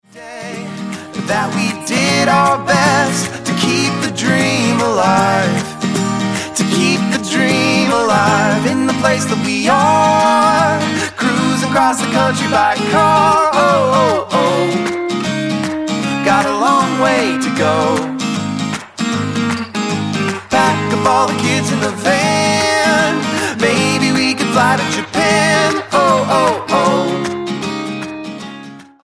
The husband and wife duo from Seattle
Folk roots group
Style: Roots/Acoustic